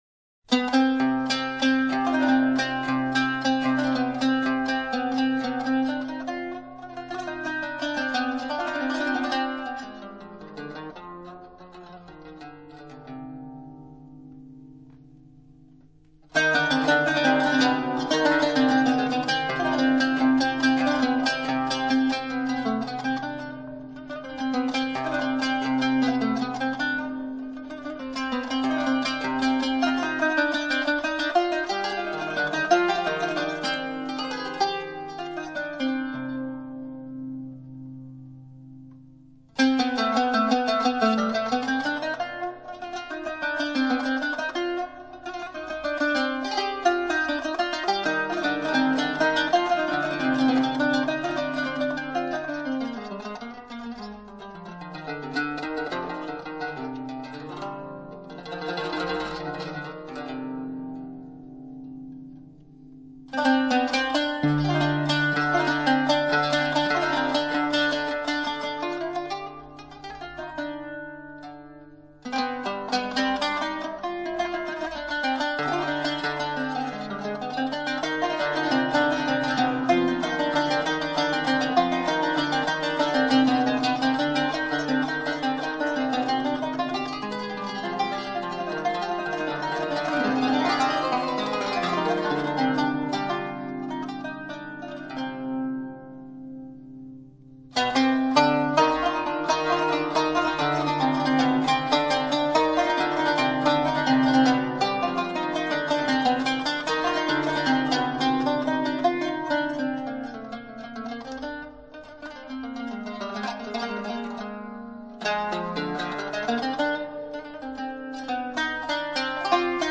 以洗练的即兴演奏形式